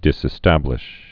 (dĭsĭ-stăblĭsh)